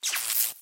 Звук замыкания глухой.ogg